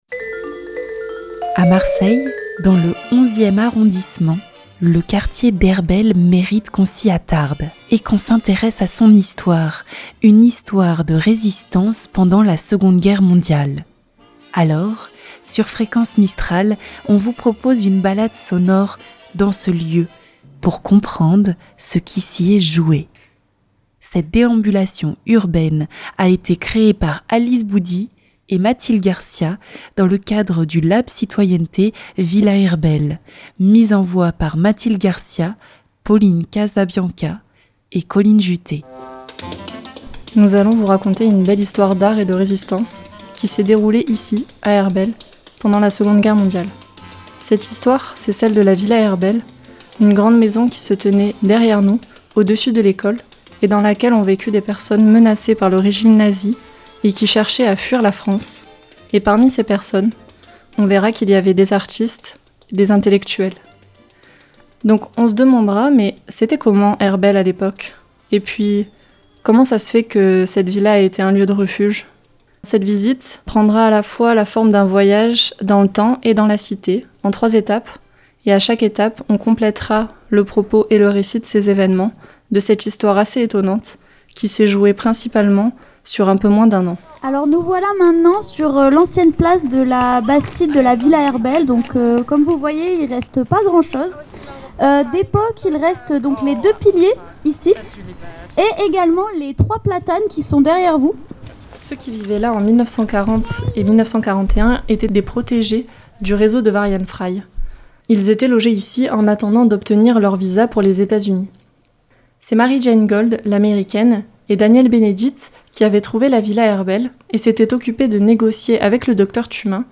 Alors, sur Fréquence Mistral on vous propose une balade sonore dans ce lieu pour comprendre ce qui s'y est joué. Le 3ème épisode se concentre sur les artistes réfugiés, les habitant.e.s de la Villa en attente de leur visa pour fuir le nazisme pendant la 2nde guerre mondiale.